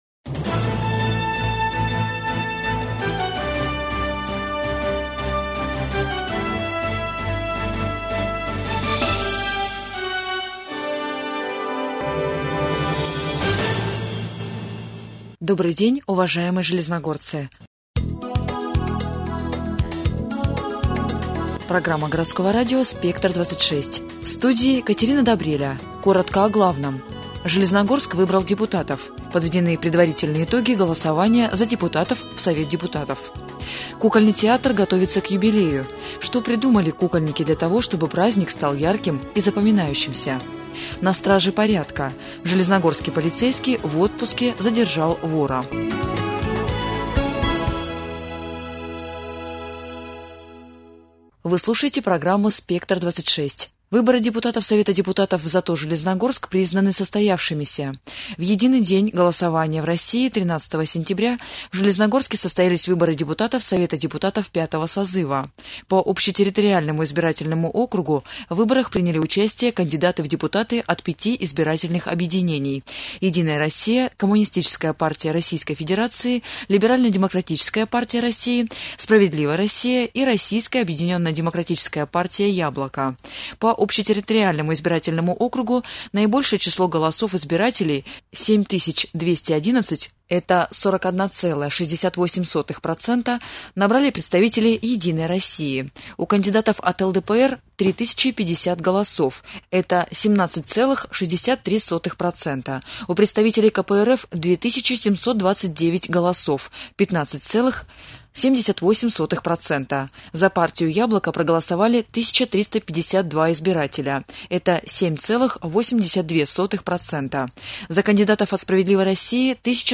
Радиопередачи В программе : • Железногорск выбрал депутатов • Кукольный театр готовится к юбилею • На страже порядка • Маткапитал - на ипотеку • Дни открытых дверей